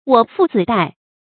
我負子戴 注音： ㄨㄛˇ ㄈㄨˋ ㄗㄧˇ ㄉㄞˋ 讀音讀法： 意思解釋： 謂夫妻同安于貧賤。